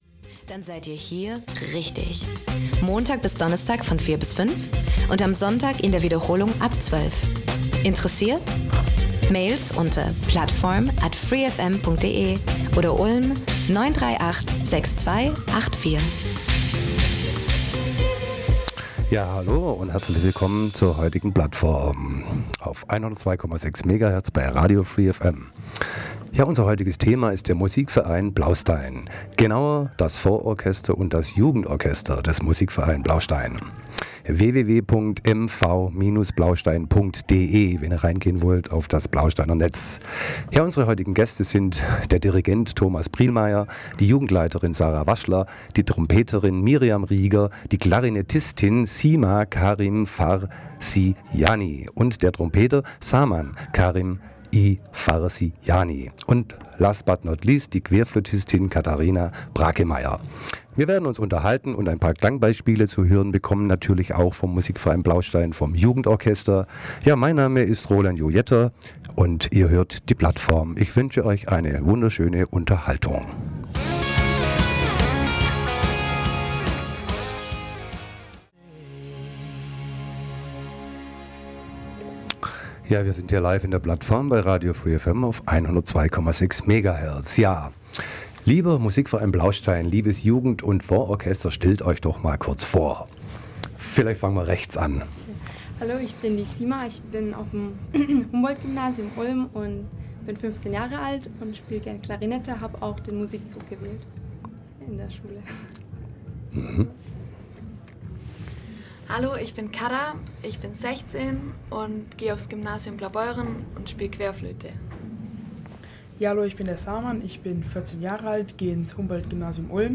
Genre Radio